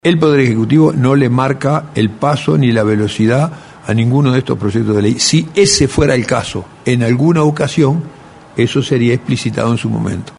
El senador Ernesto Agazzi, coordinador de la bancada de Senadores del FA, indicó en conferencia de prensa que el presidente insistió en que la entrega de estos proyectos no implica su consideración de forma urgente.